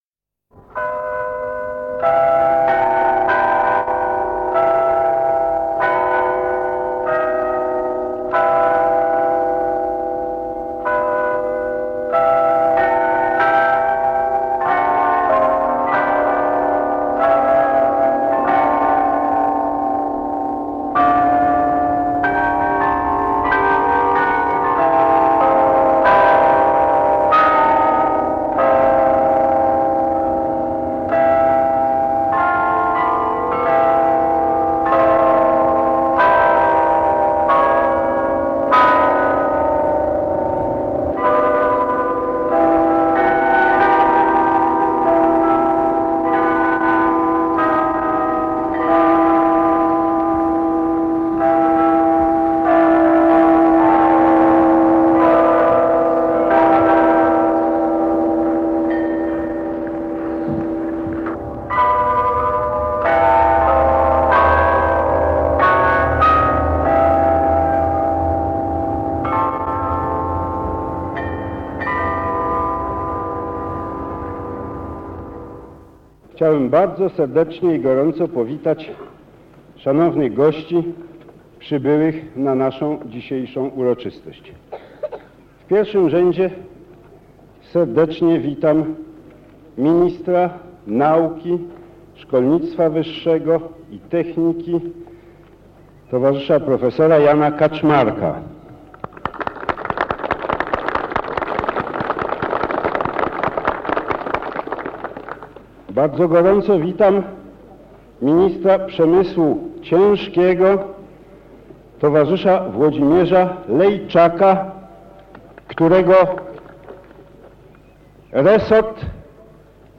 Wmurowanie kamienia węgielnego pod gmach Instytutu Okrętowego: relacja [dokument dźwiękowy] - Pomorska Biblioteka Cyfrowa